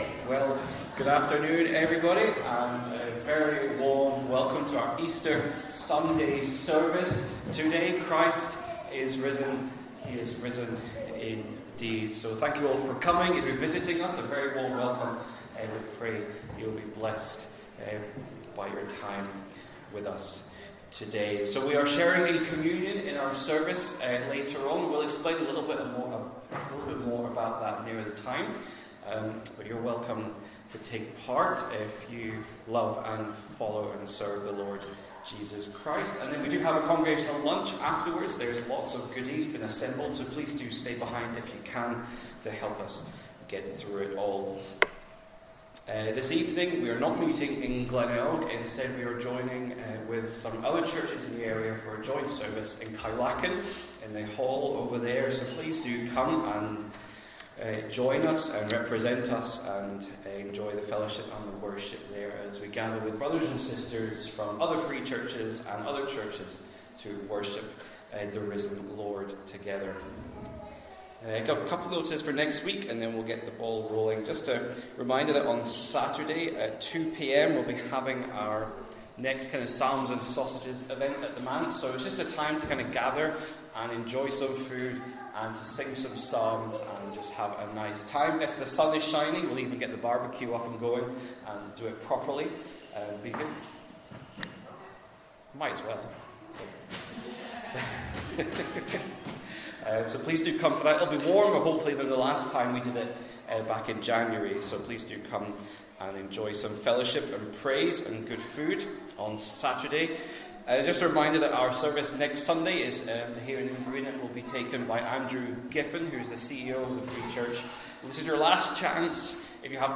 1 Corinthians 15:1-34 Service Type: Inverinate AM Download Files Bulletin « Amos
Easter-Service.mp3